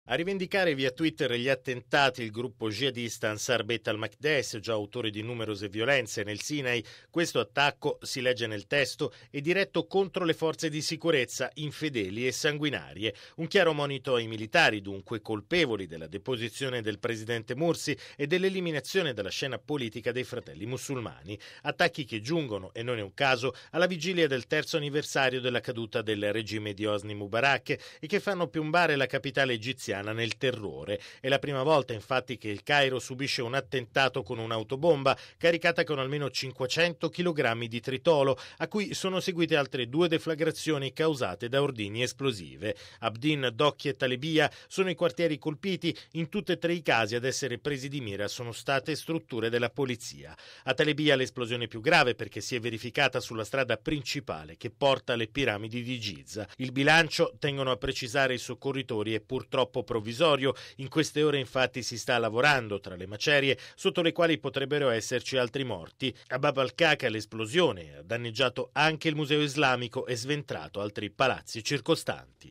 Radiogiornale del 24/01/2014 - Radio Vaticana